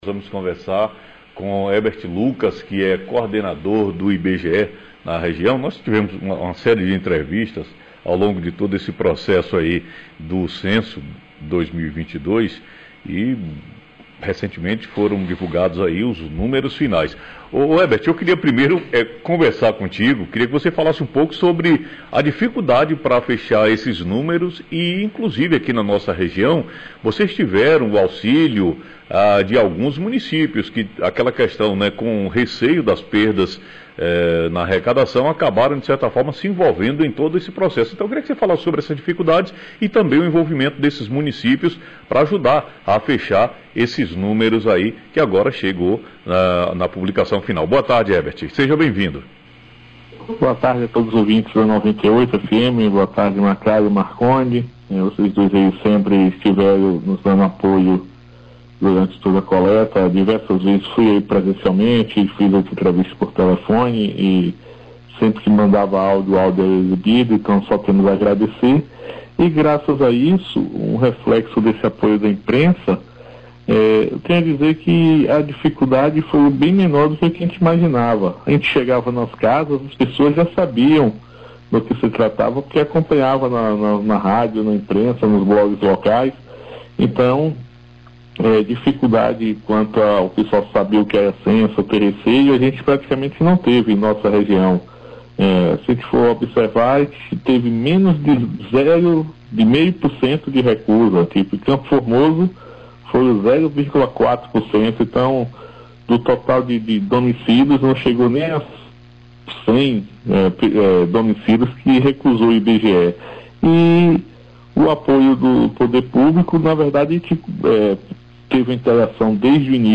Em entrevista ao vivo pelo telefone